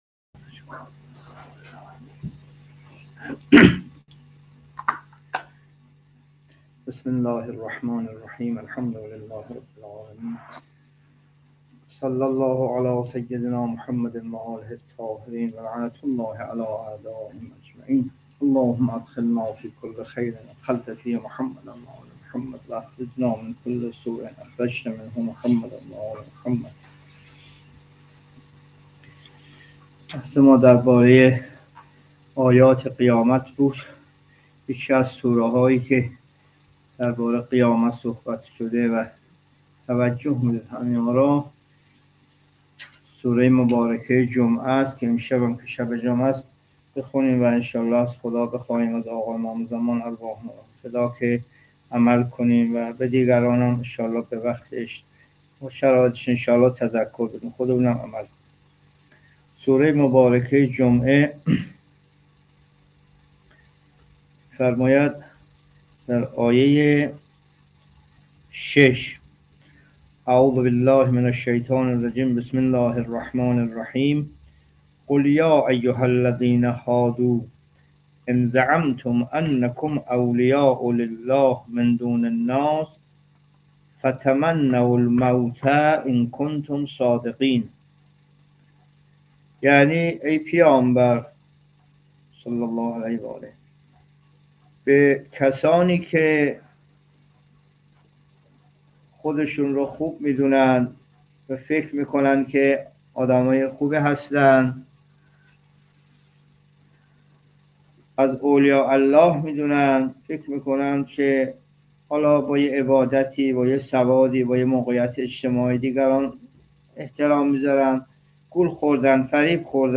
جلسه تفسیر قرآن (28) سوره جمعه